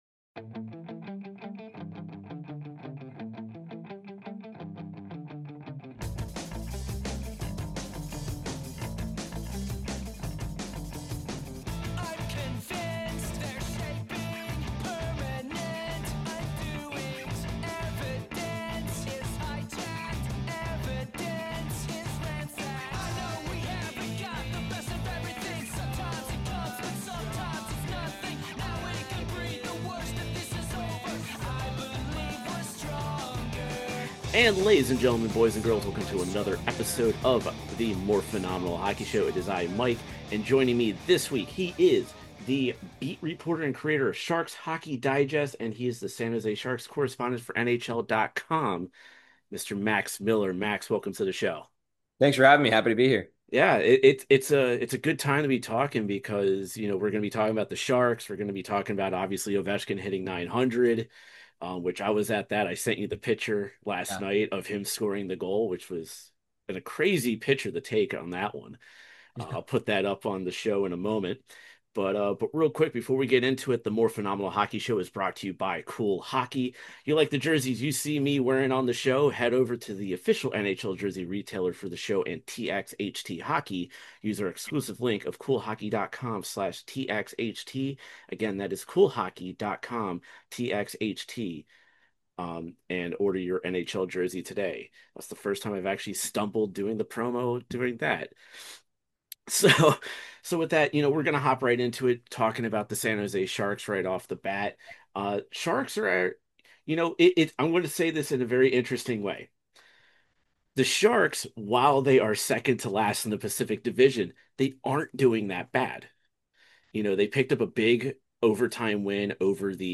Ladies and Gentlemen welcome to The Morphinominal Hockey Show for some fun hockey conversations with actors from the Power Rangers and TMNT franchises